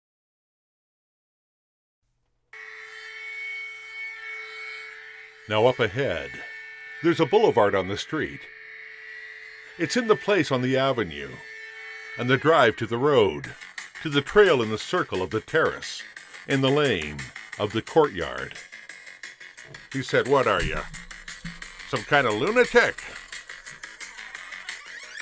• T110-1 美式英语 男声 please save 激情激昂|大气浑厚磁性|沉稳|低沉|娓娓道来